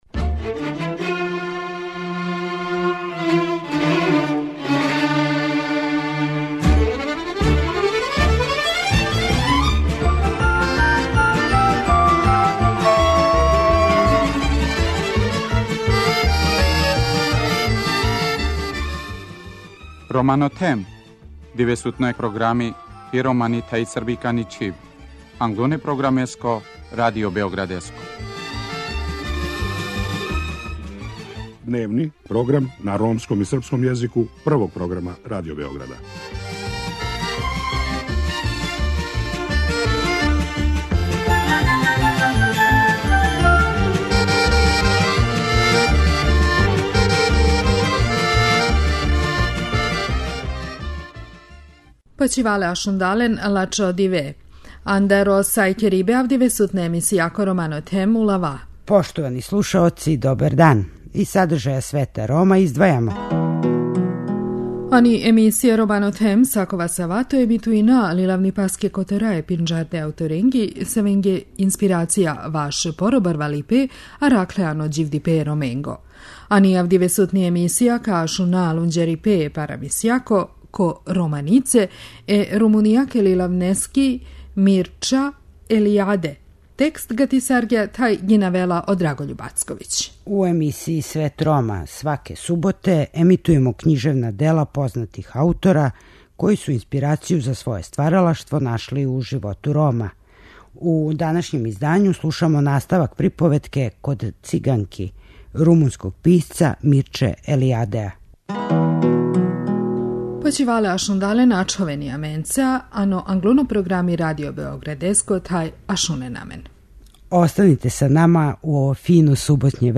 У емисији Свет Рома, сваке суботе емитујемо књижевна дела разних аутора којима су Роми били инспирација.